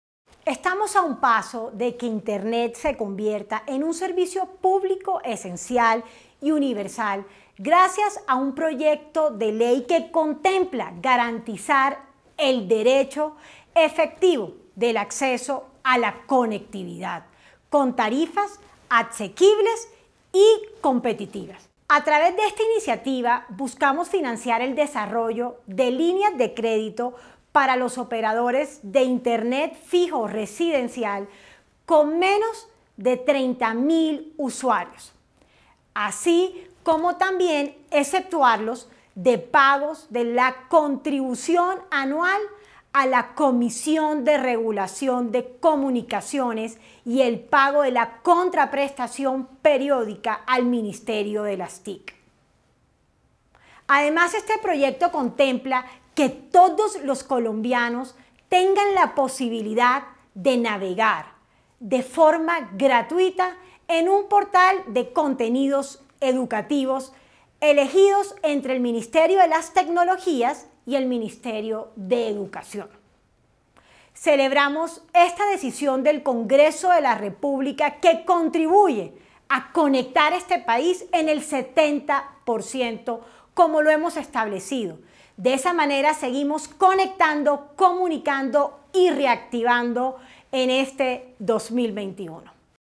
Declaración Karen Abudinen, ministra TIC.